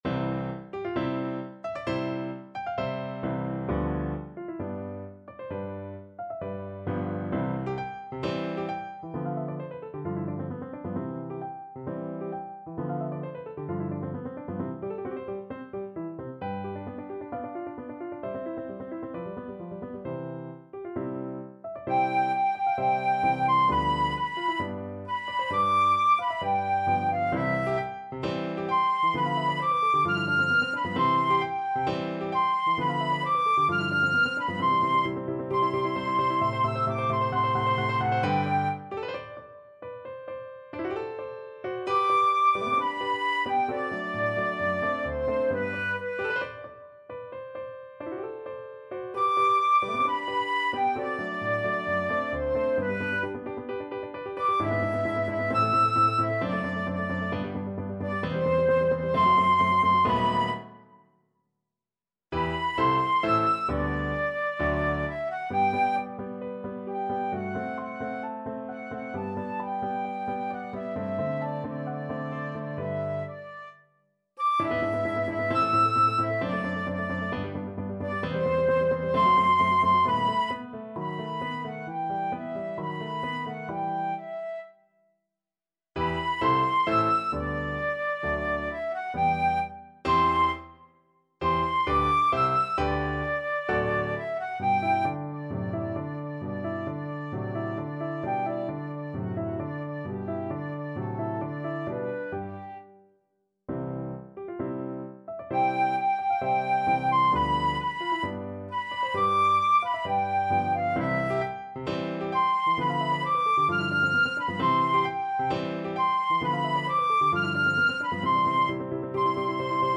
Classical Mozart, Wolfgang Amadeus Ah! chi mi dice mai from Don Giovanni Flute version
C major (Sounding Pitch) (View more C major Music for Flute )
4/4 (View more 4/4 Music)
~ = 132 Allegro (View more music marked Allegro)
Flute  (View more Intermediate Flute Music)
Classical (View more Classical Flute Music)